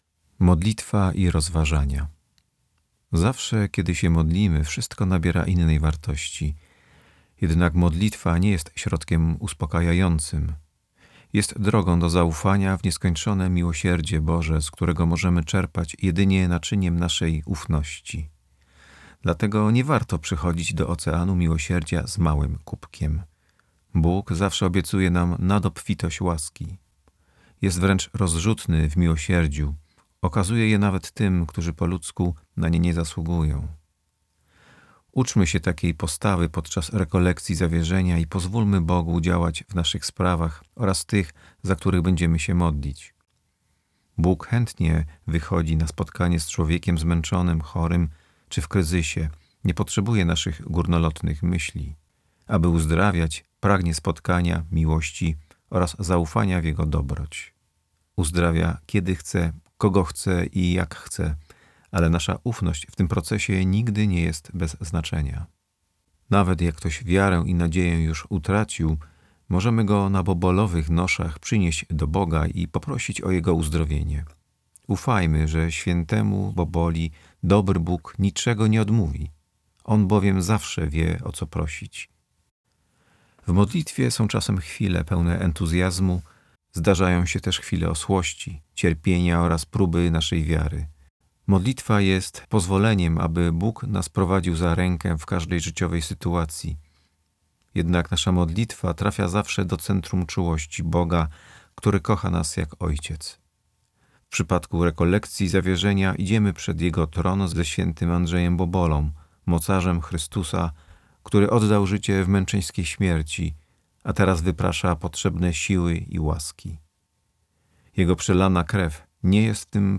MODLITWA I ROZWAŻANIA